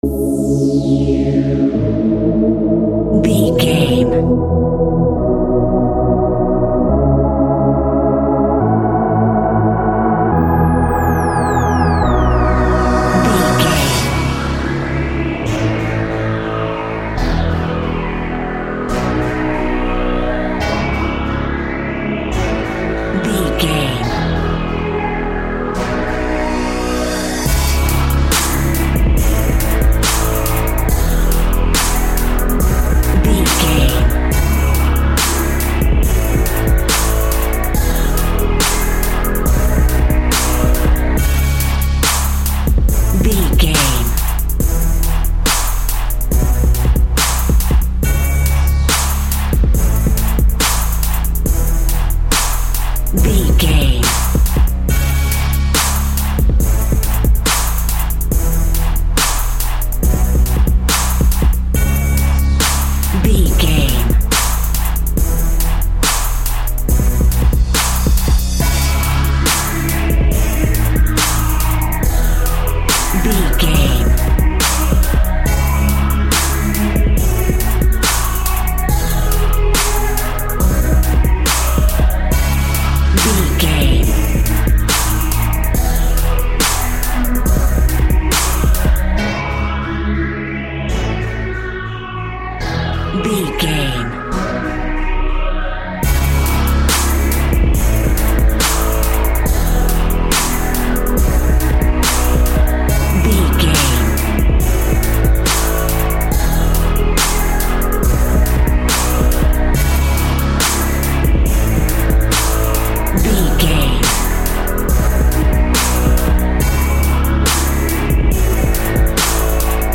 Hip Hop VS Epic.
Epic / Action
Aeolian/Minor
C#
hip hop instrumentals
laid back
hip hop drums
hip hop synths
piano
hip hop pads